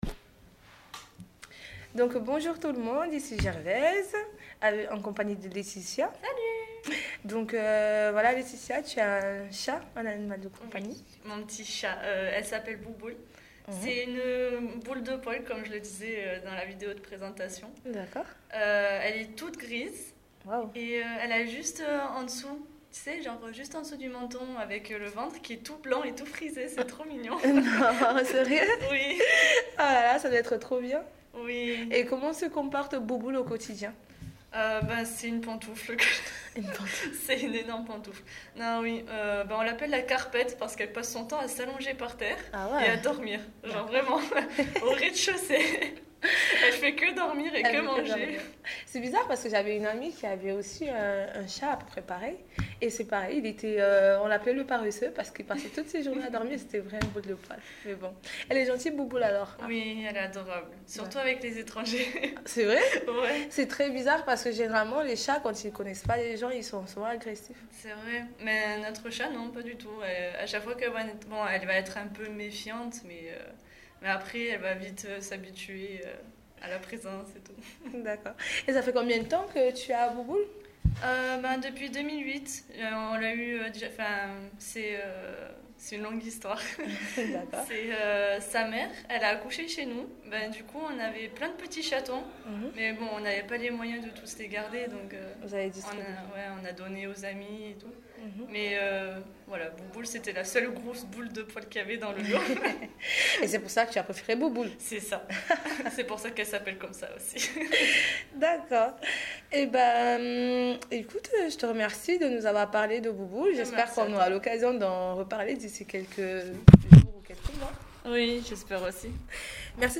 8. Et tout : style oral et familier.